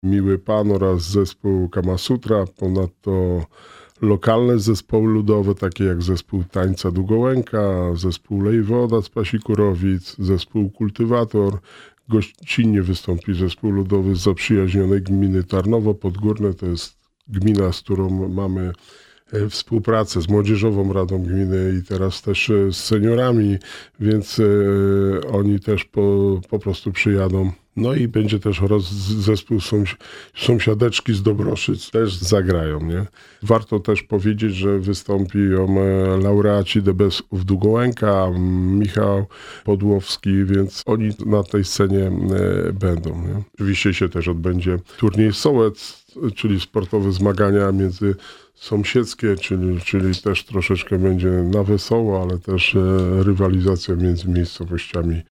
– Będą gwiazdy polskiej sceny muzycznej – zachęca Wójt Gminy Długołęka.